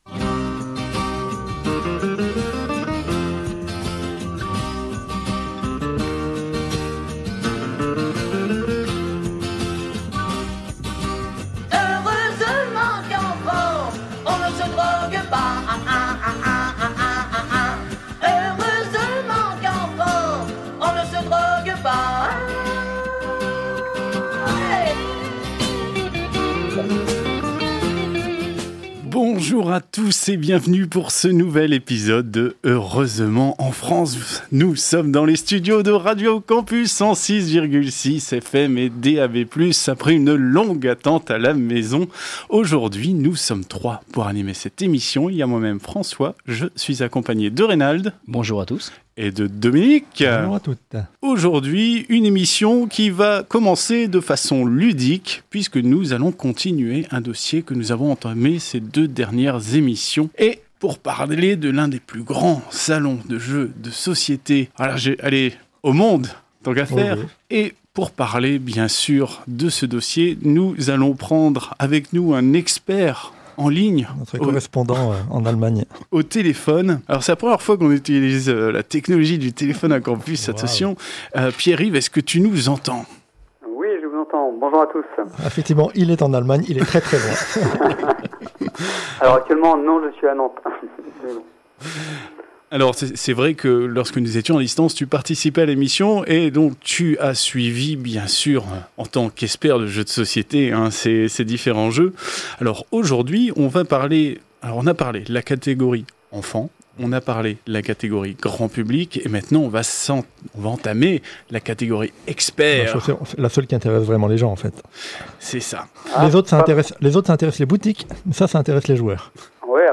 Au sommaire de cet épisode diffusé le 6 juin 2021 sur Radio Campus 106.6 :